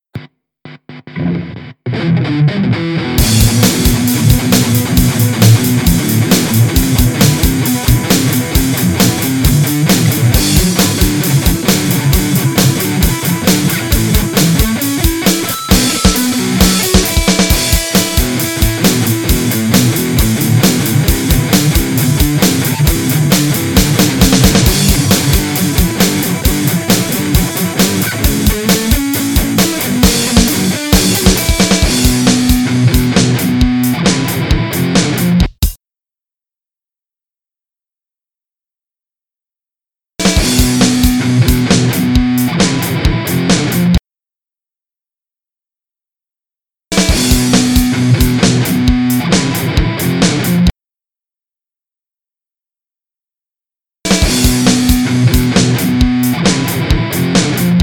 guitar_DEMO_DEMO23.mp3